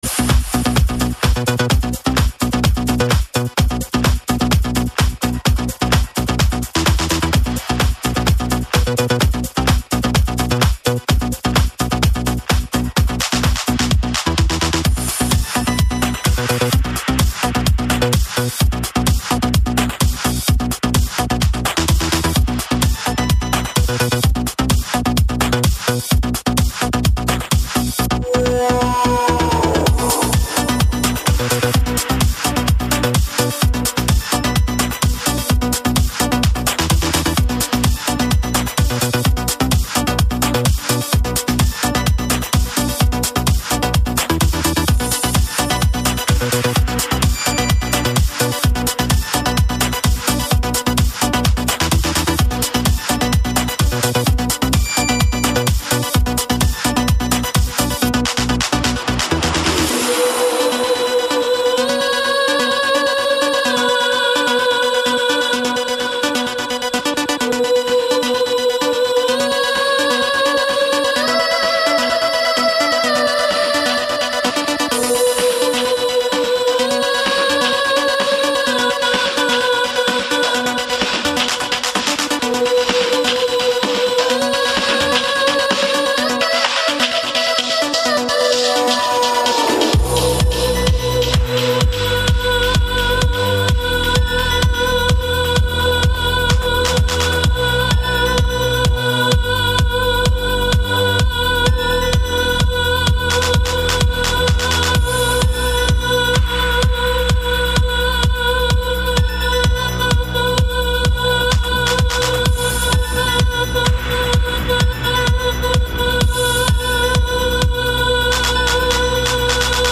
друзья! ну просто офигенный (electro house 2007-2008)